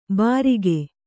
♪ bārige